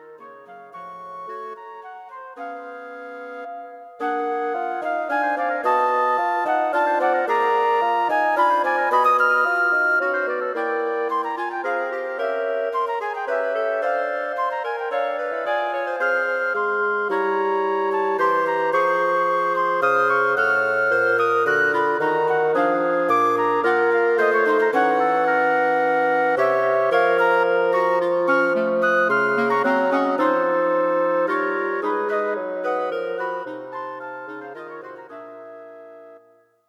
Wind Quartet for Concert performance